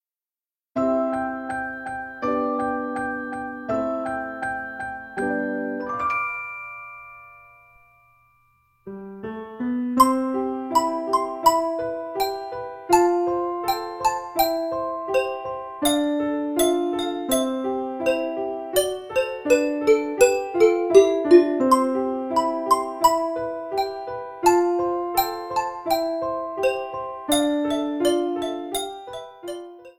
Opening theme
The ending cuts off and transitions to DEMO_OPENING_CUT02.